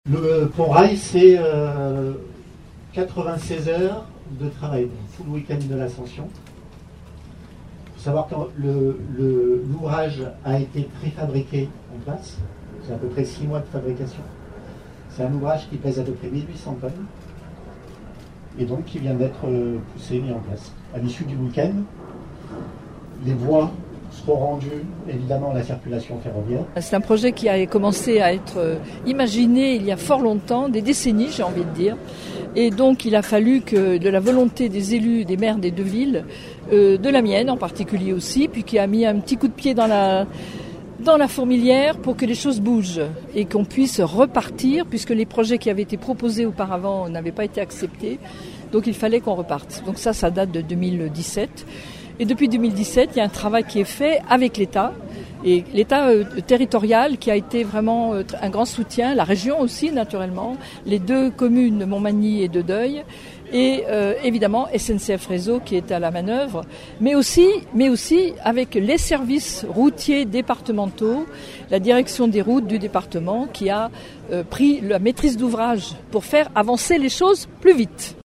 Sur place les réactions de :
Marie-Christine CAVECCHI, présidente du conseil départemental du Val d’Oise